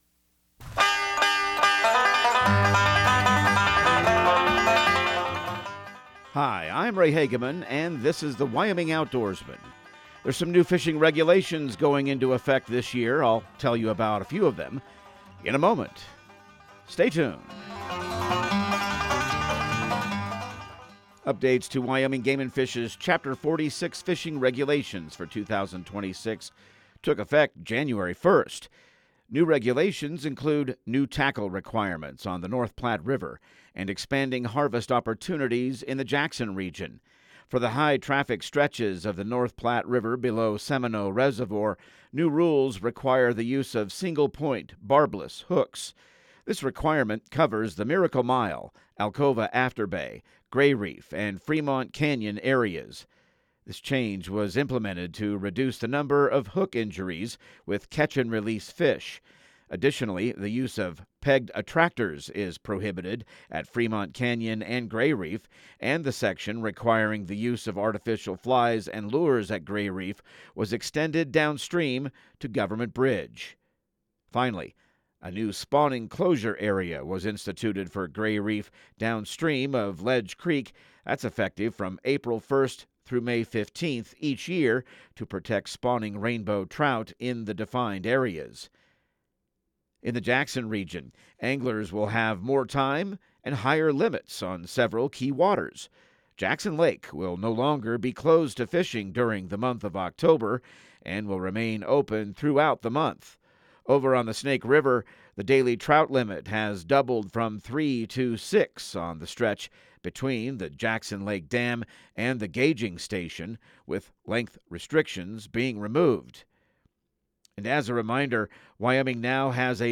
Radio news | Week of February 16